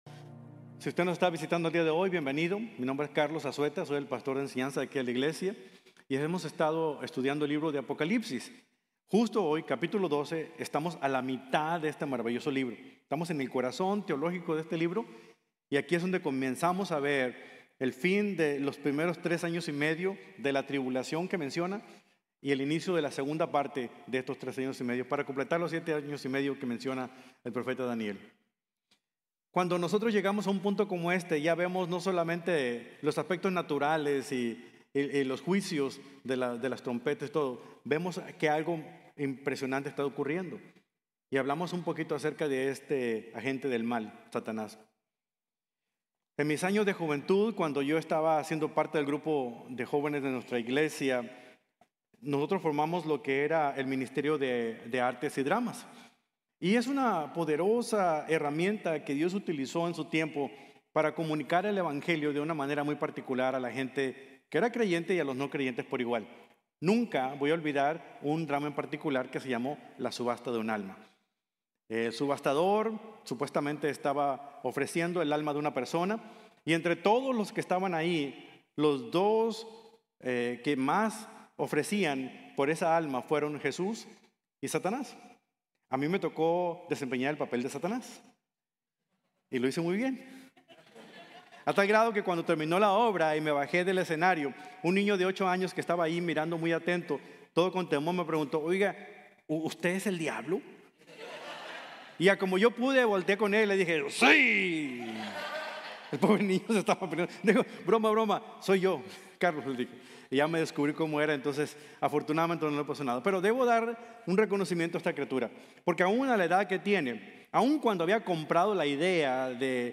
El Conflicto Cósmico | Sermon | Grace Bible Church